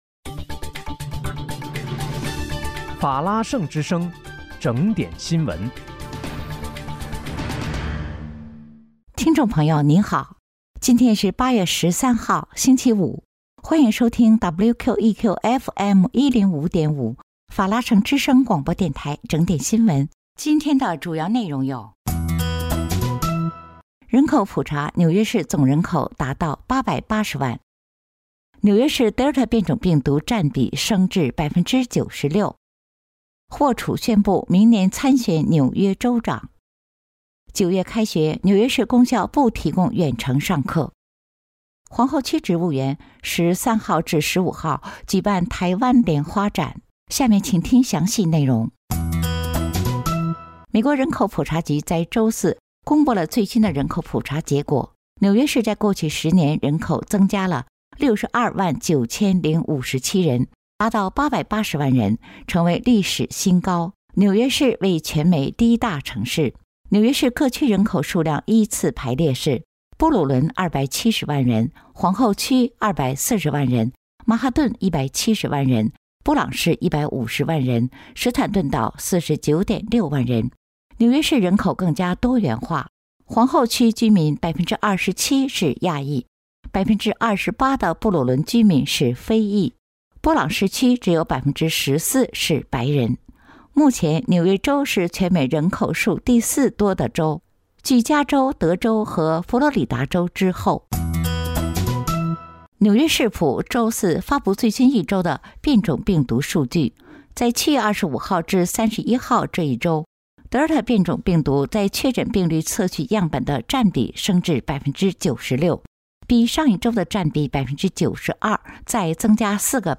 8月13日（星期五）纽约整点新闻